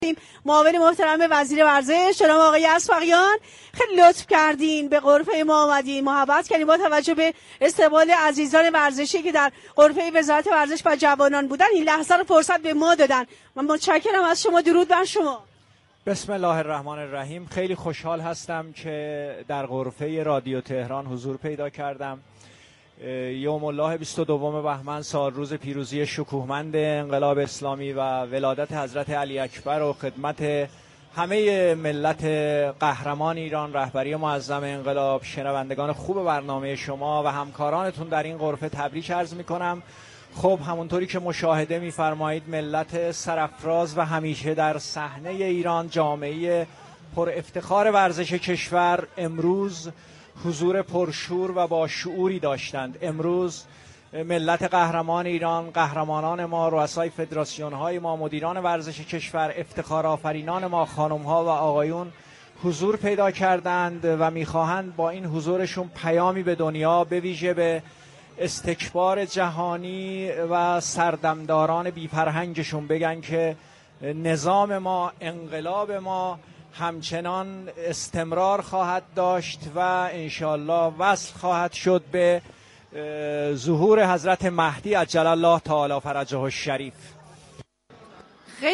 به گزارش پایگاه اطلاع رسانی رادیو تهران، سیدمحمد شروین اسبقیان معاون توسعه ورزش قهرمانی و حرفه‌ای وزارت ورزش و جوانان كشور در گفت و گو با «اینجا تهران است» ویژه برنامه چهل و ششمین سالروز پیروزی انقلاب اسلامی اظهار داشت: یوم الله 22 بهمن سالروز پیروزی انقلاب اسلامی را تبریك عرض می‌كنیم.